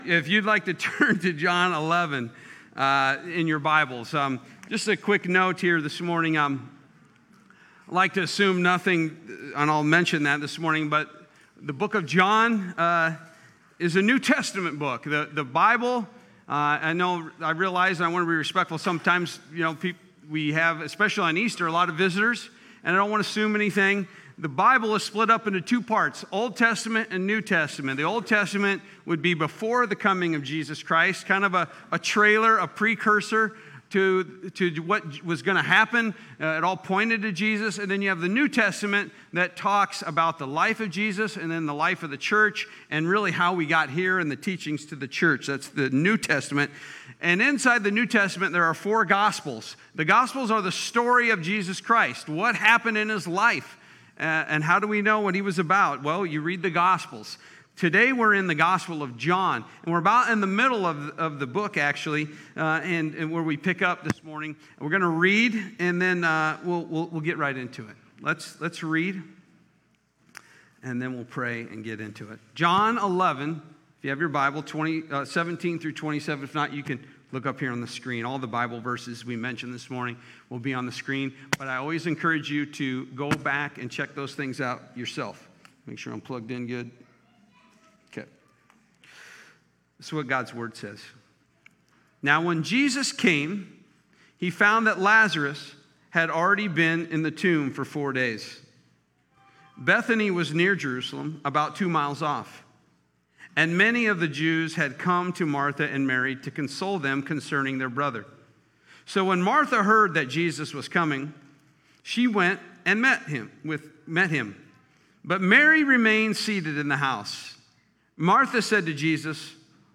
Easter Service – Luke 11:17-27